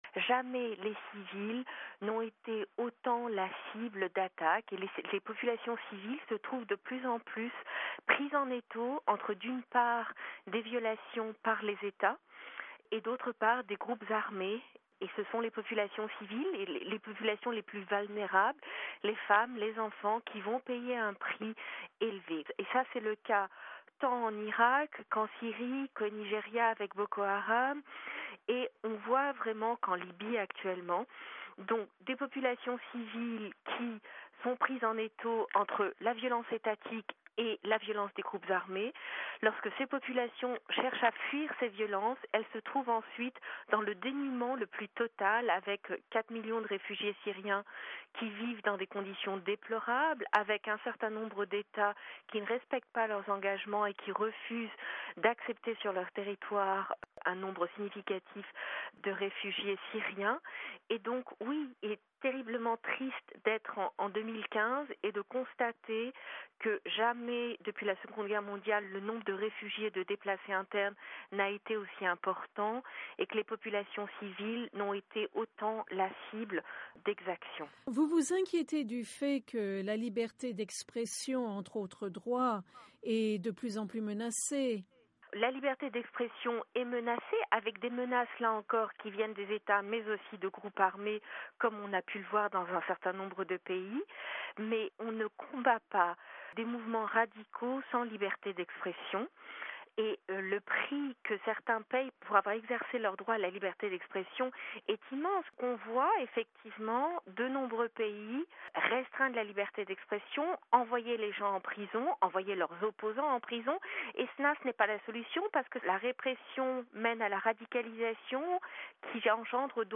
Dans une interview avec la Voix de l’Amérique (VOA), elle a souligné que les civils sont pris en étaux entre les Etats d’une part, et les groupes armés.